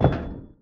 Step1.ogg